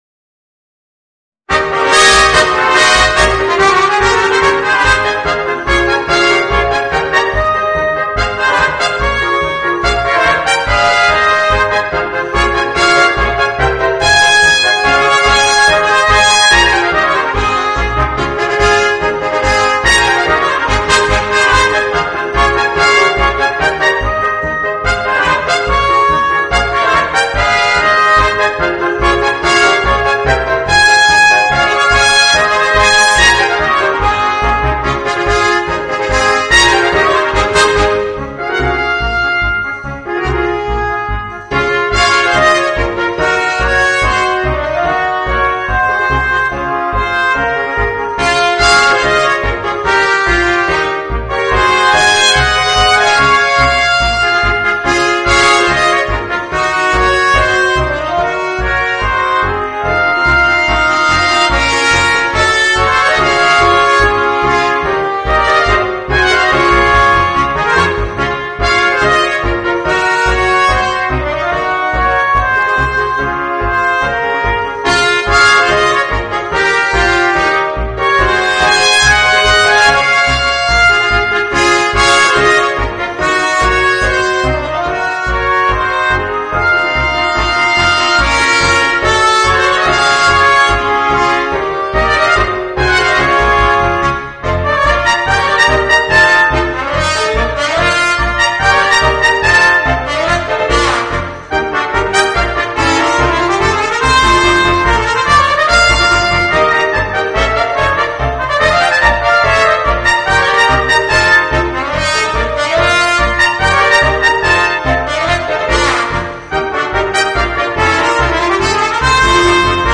Voicing: 4 Trumpets and Piano